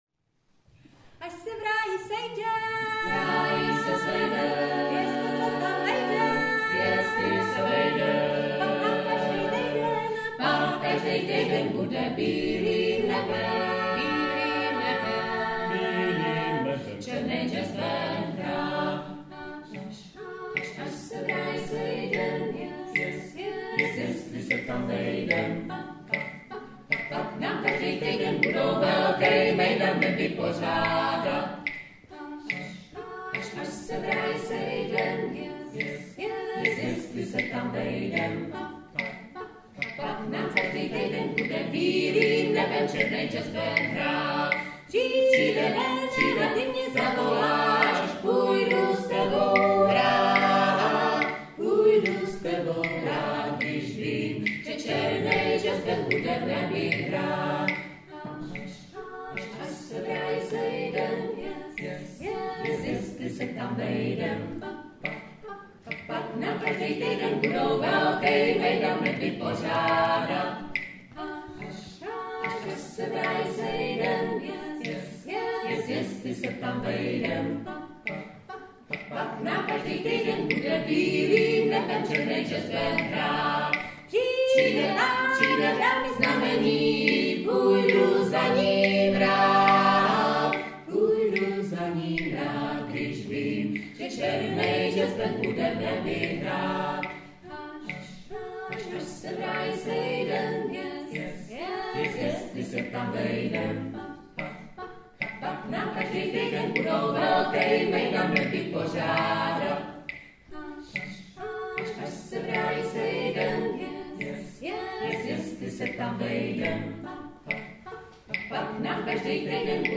vlh2-acapella.mp3